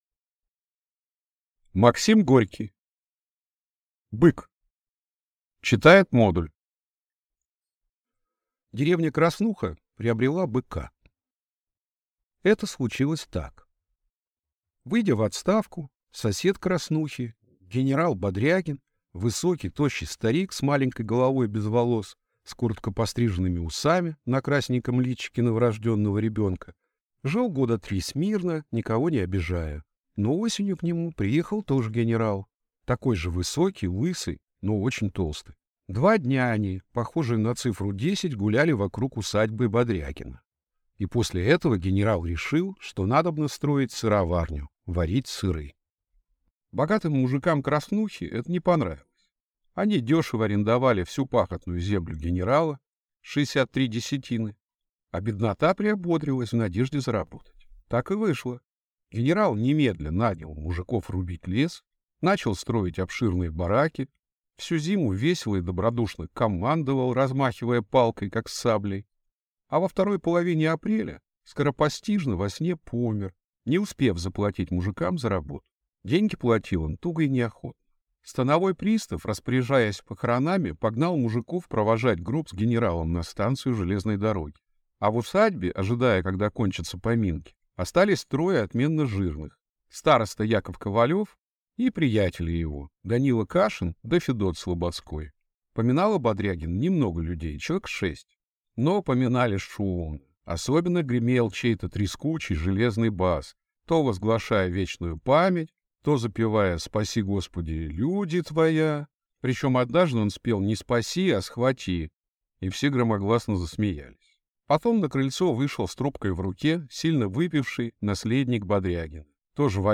Аудиокнига Бык | Библиотека аудиокниг